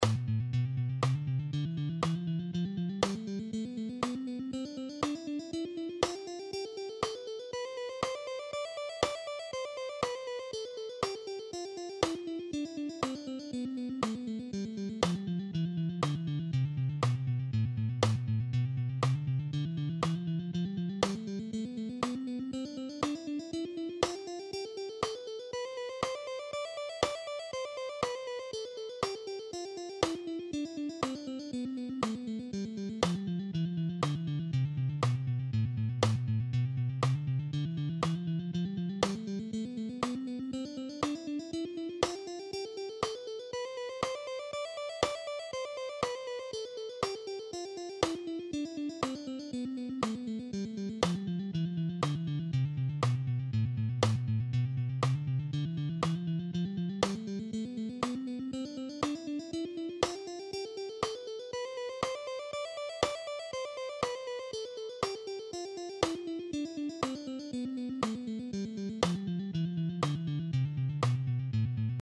All these guitar exercises are in ‘C’
Aeolian Tril Technique Guitar Lesson
6.-Aeolian-Tril-Technique-Guitar-Lesson.mp3